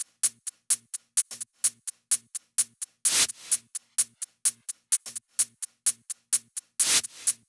VFH1 128BPM Southern Kit